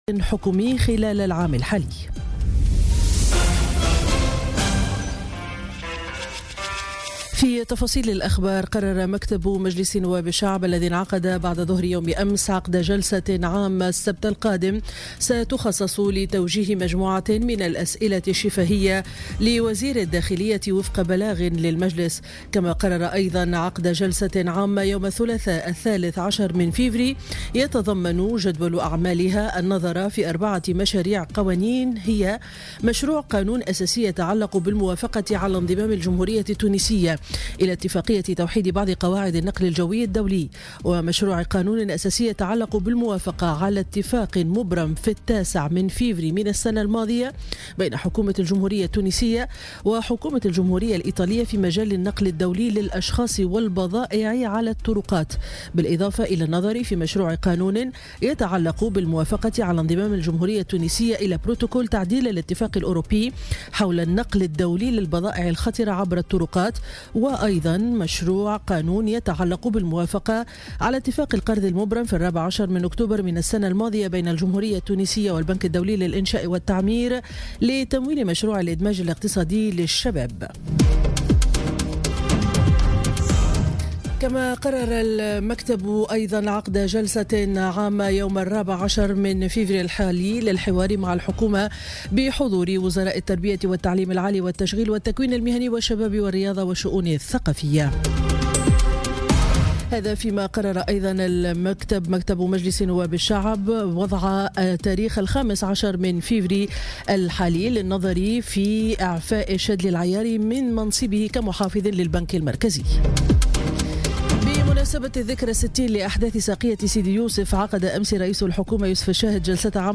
نشرة أخبار السابعة صباحا ليوم الجمعة 9 فيفري 2018